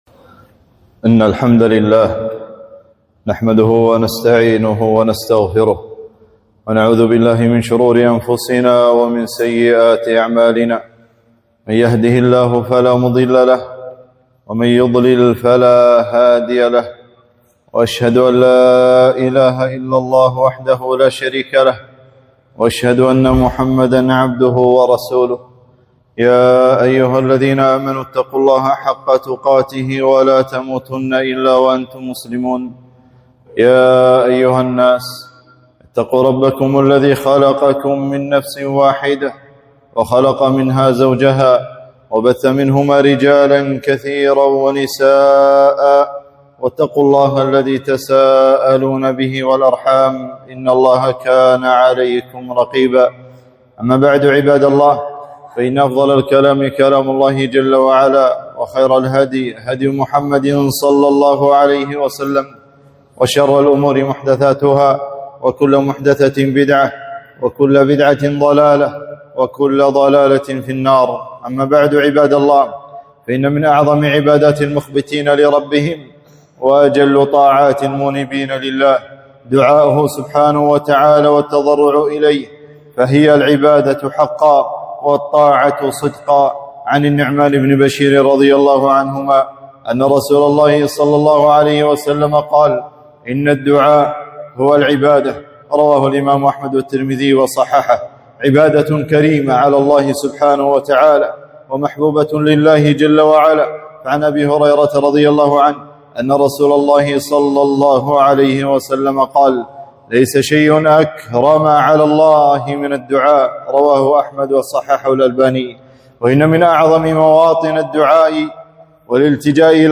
خطبة - الدعاء وقت الحرب